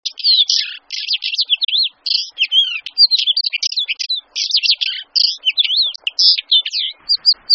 En cliquant ici vous entendrez le chant du rouge-queue à Front Blanc.
Le Rouge-Queue à front blanc